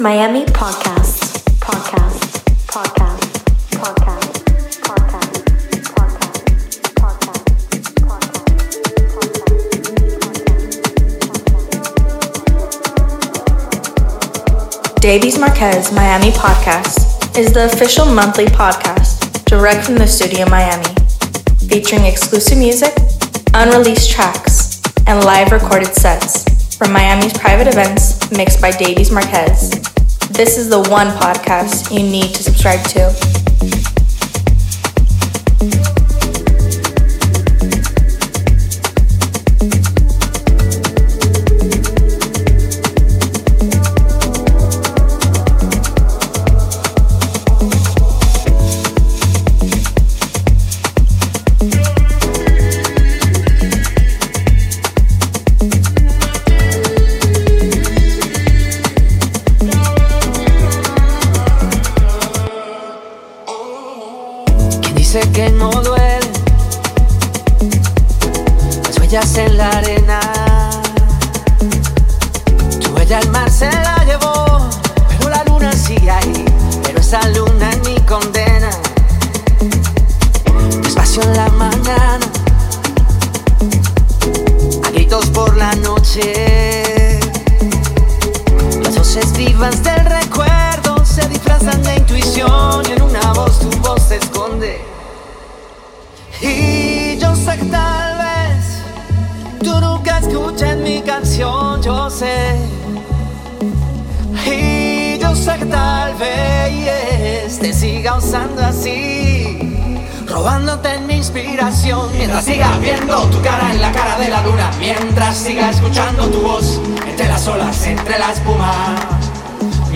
set recorded at Private Event Miami Beach
Deep House
tech house